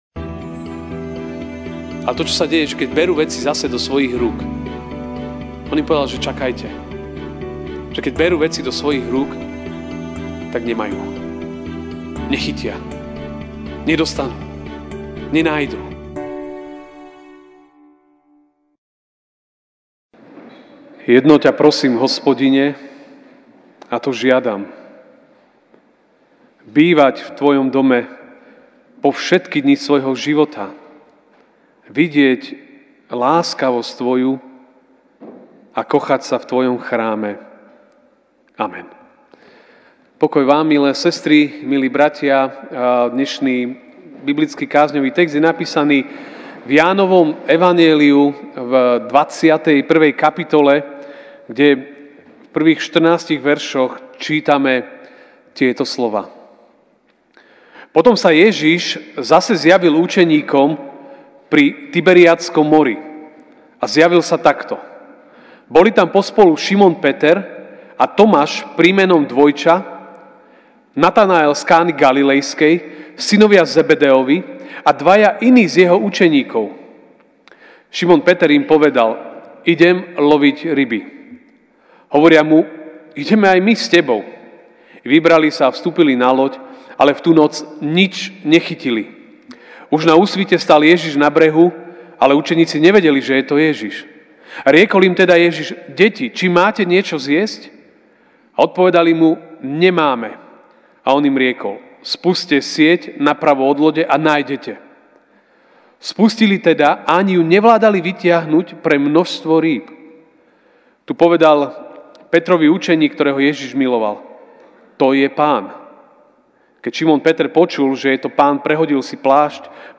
máj 05, 2019 Zjavenie dobrého Pastiera MP3 SUBSCRIBE on iTunes(Podcast) Notes Sermons in this Series Ranná kázeň: Zjavenie dobrého Pastiera (J 21, 1-14) Potom sa Ježiš zase zjavil učeníkom pri Tiberiadskom mori.